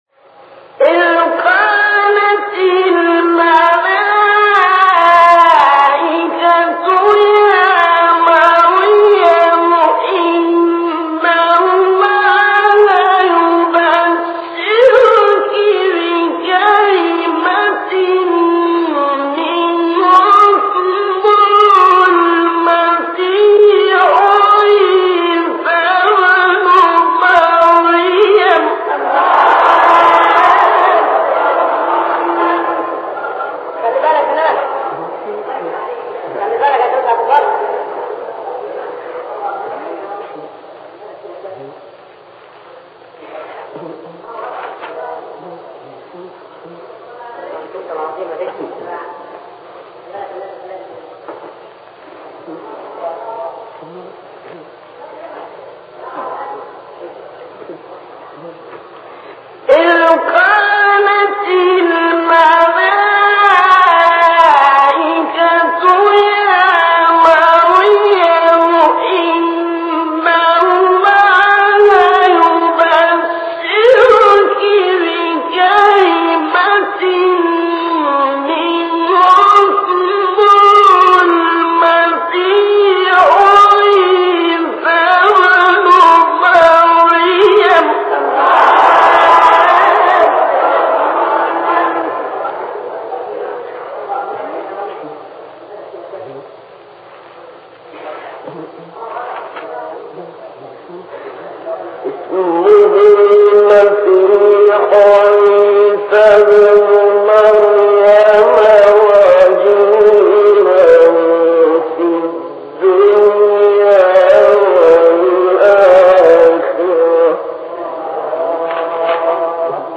مقام سه گاه استاد شعیشع | نغمات قرآن | دانلود تلاوت قرآن